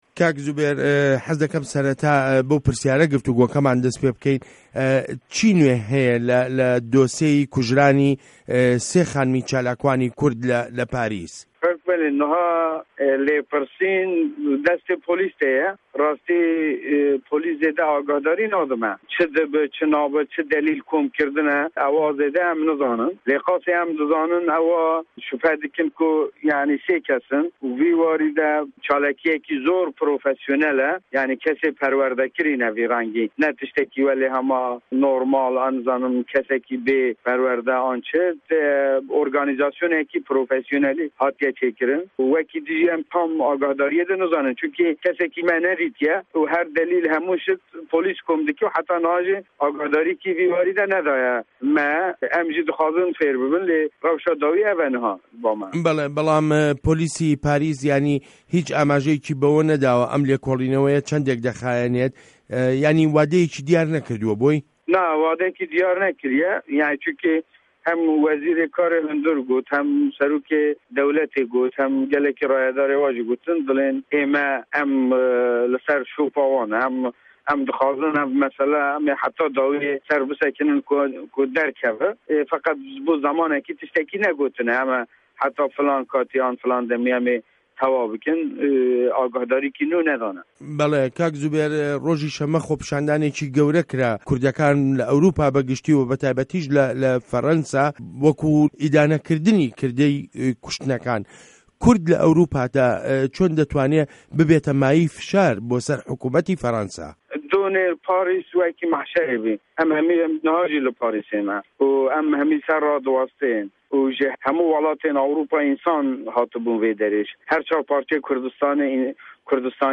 Hevpeyvîn bi Zubeyir Aydar re